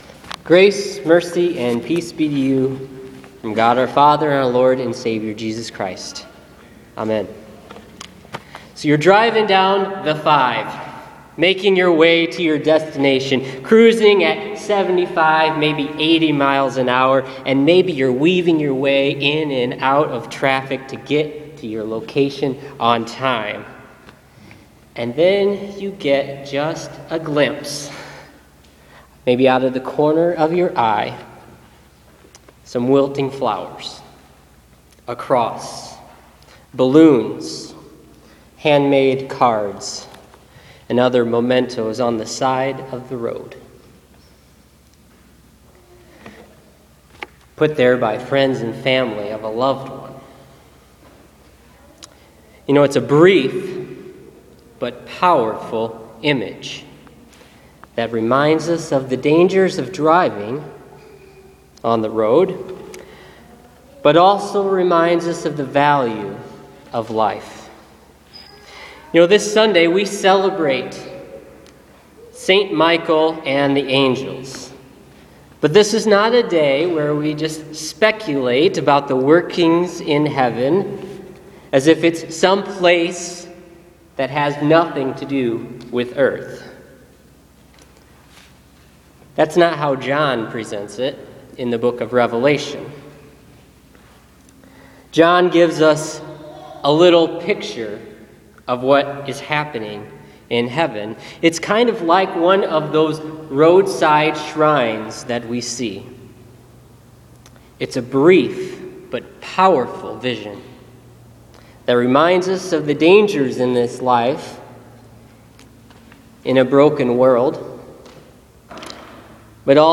Listen to this week’s sermon on Revelation 12:7-12 for the Feast of St. Michael and the Angels.